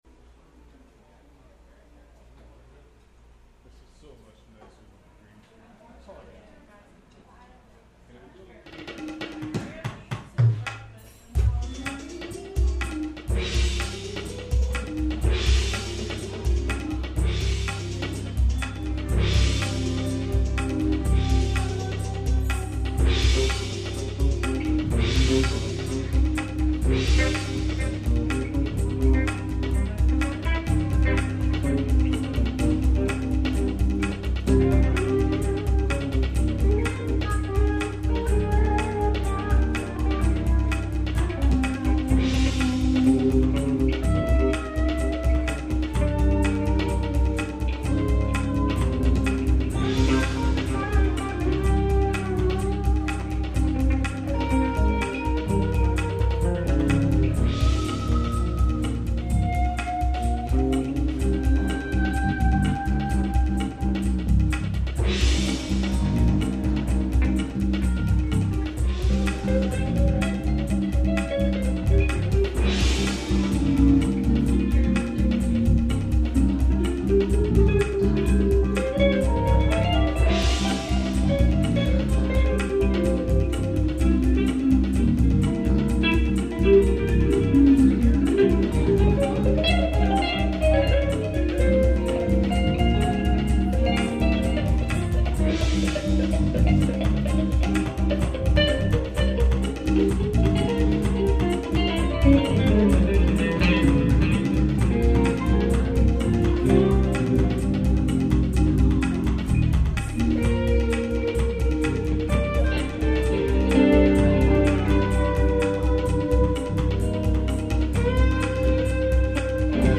"Live" at the Metaphor Cafe in Escondido, CA
Guitar
Bass
electronic Drums